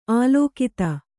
♪ ālōkita